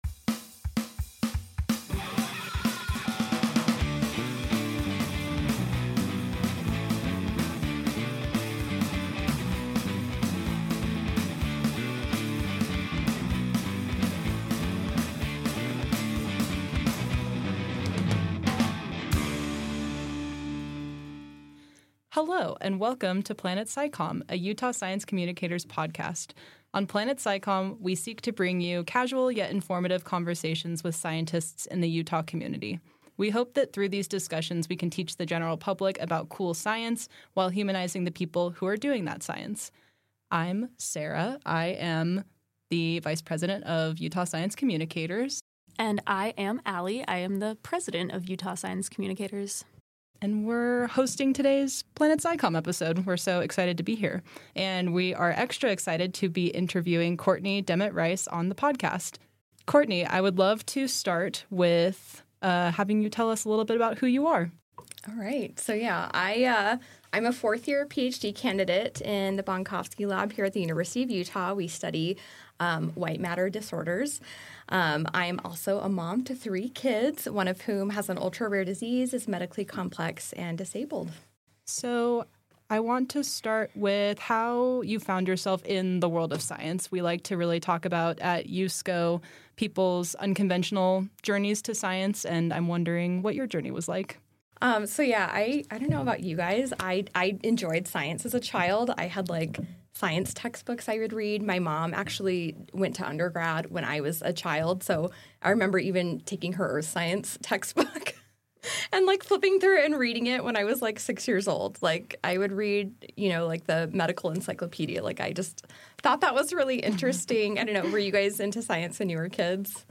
Be braver, be bolder: an interview